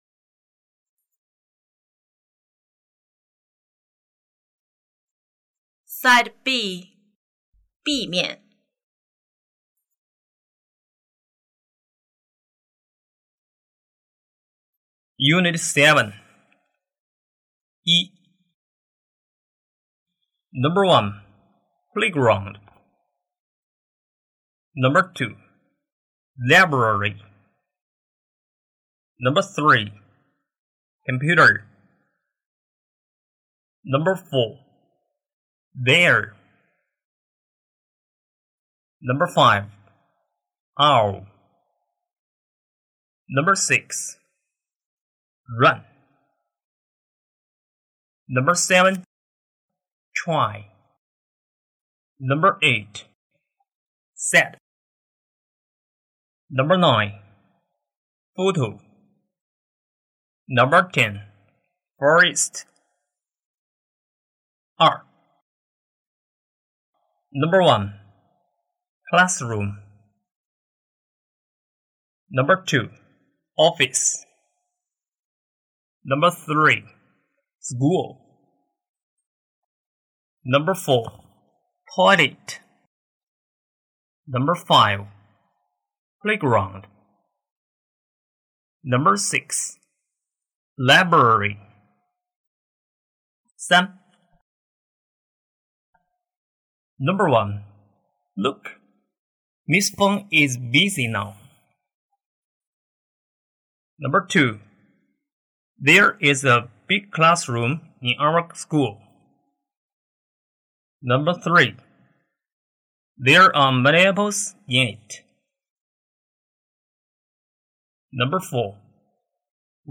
英语听力